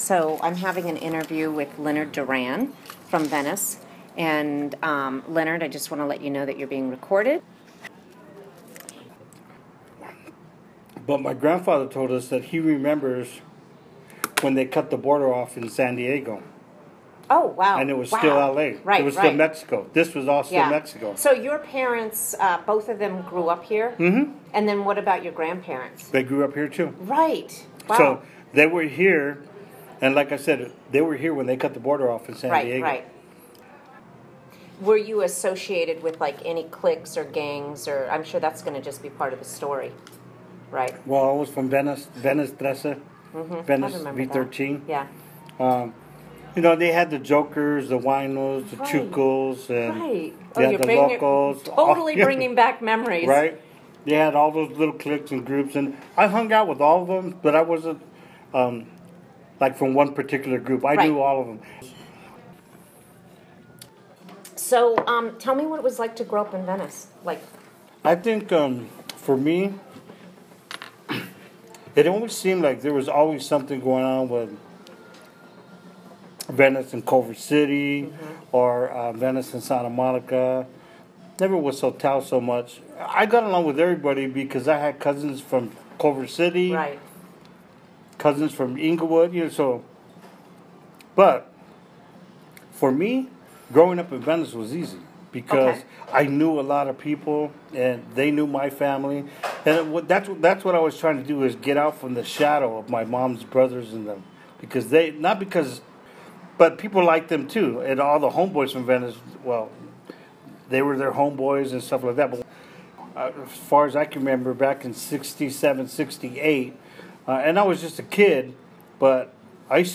Interview
This 9 min. audio was recorded at the Ihop in Marina del Rey April 2016 | Content may be sensitive to children.